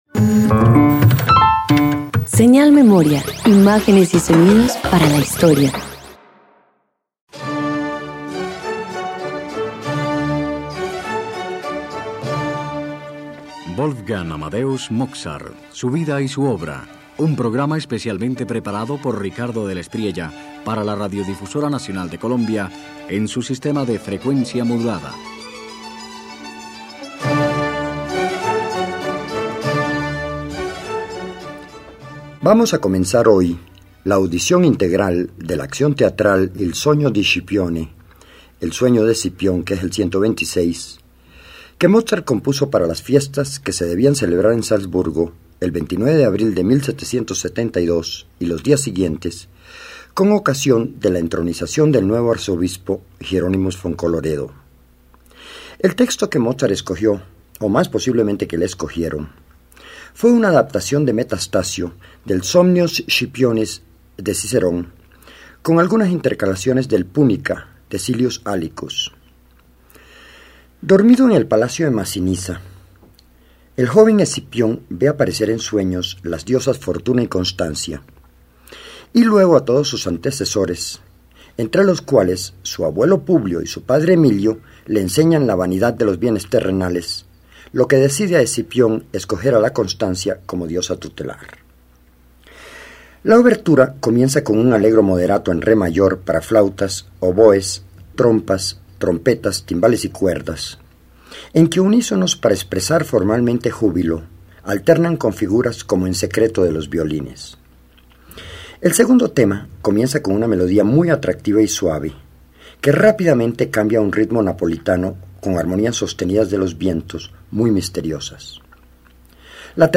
El joven compositor convierte la filosofía en teatro. En “El sueño de Escipión”, las voces representan virtudes, los coros, constelaciones. La orquesta respira solemnidad de ceremonia, pero el alma es íntima y Mozart encuentra belleza en el deber.